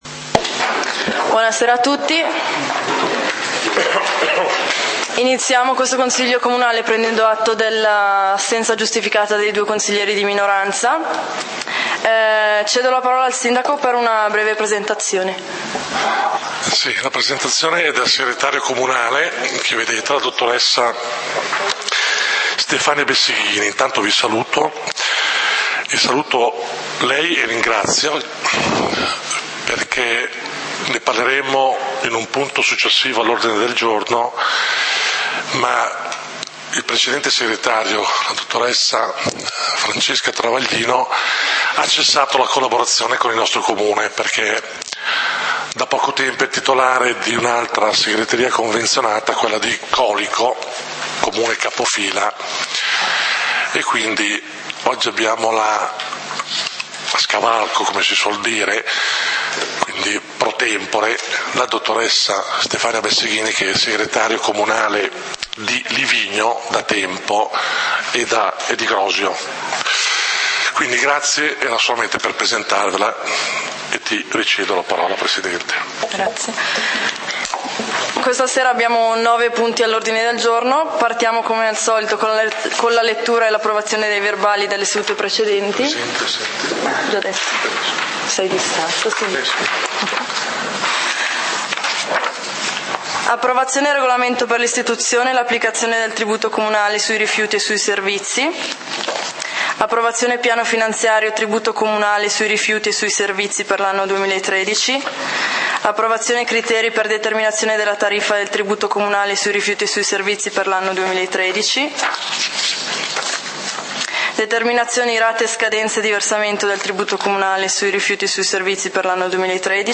Consiglio comunale di Valdidentro del 14 Ottobre 2013